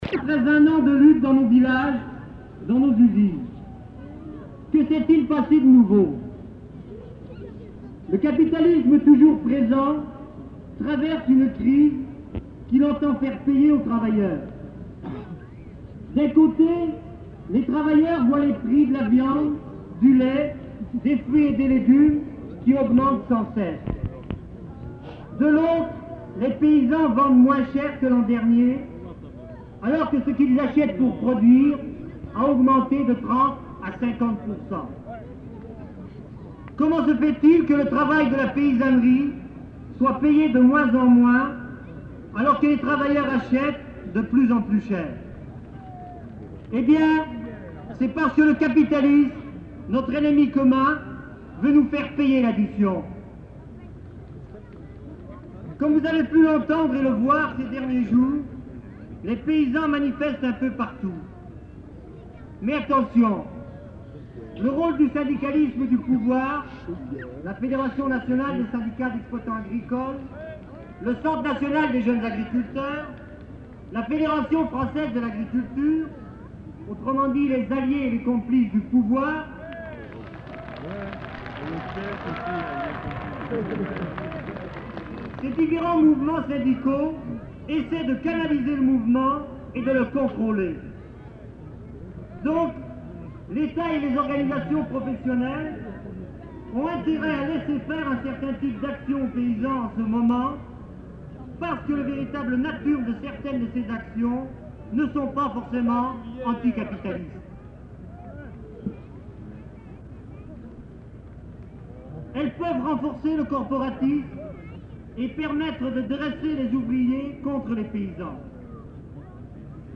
Numéro d'inventaire : 785-12 Plage CD/Page recueil : 3 Durée/Pagination : 11min 55s Dép : 12 Lieu : [sans lieu] ; Aveyron Date : 1974 Genre : parole Notes consultables : Les allocuteurs ne sont pas identifiés. Ecouter-voir : archives sonores en ligne Contenu dans [enquêtes sonores] Larzac 1974